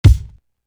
Neighborhood Watch Kick.wav